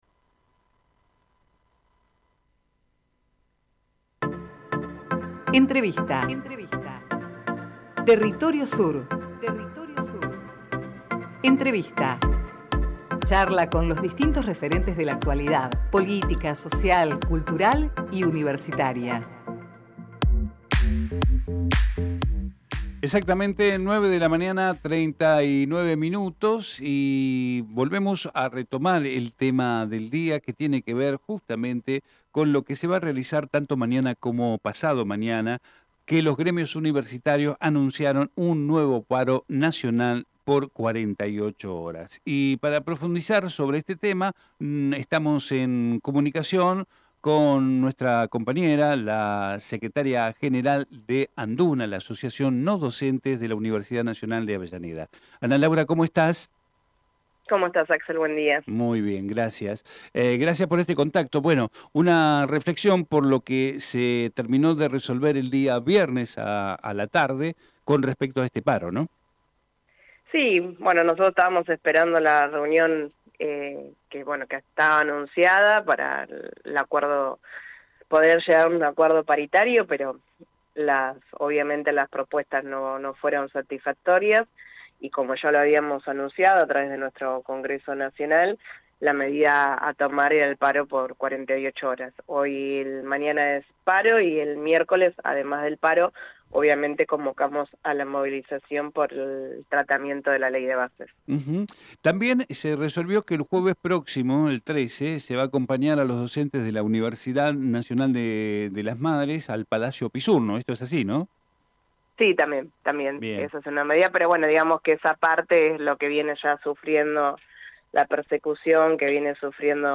Compartimos con ustedes la entrevista realizada en " Territorio Sur "